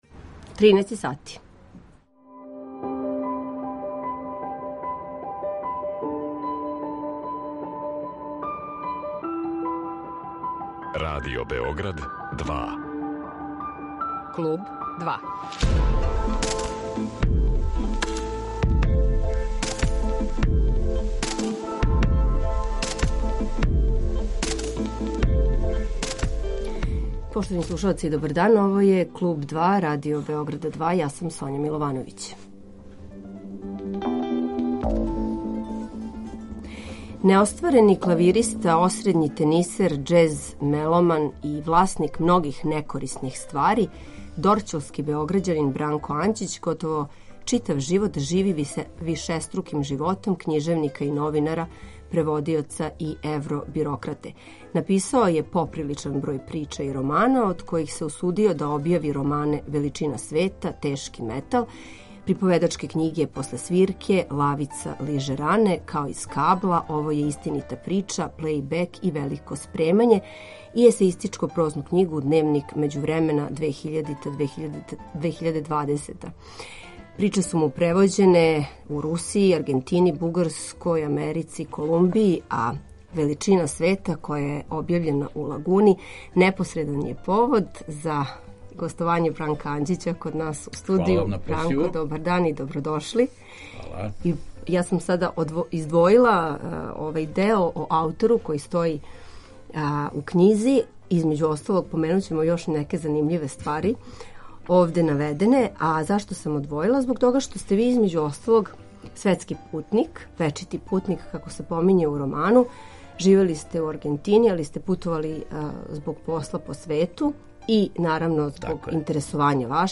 У Клубу 2, гост је писац